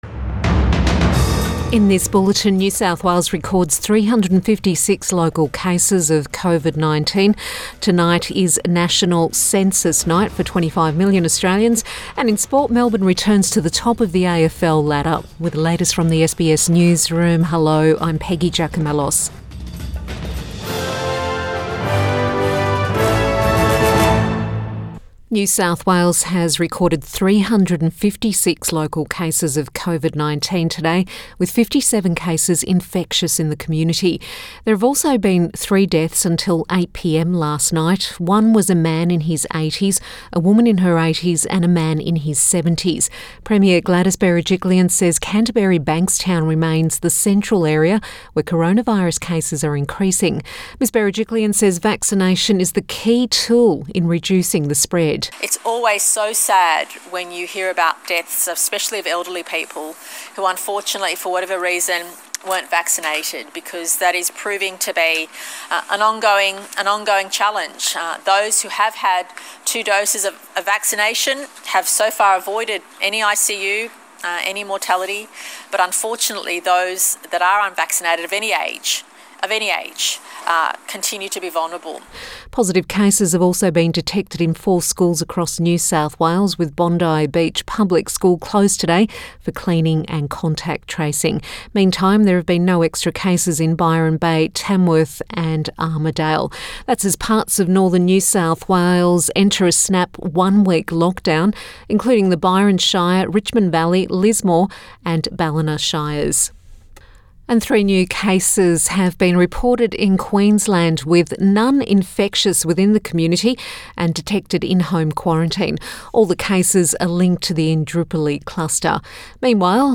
Midday bulletin 10 August 2021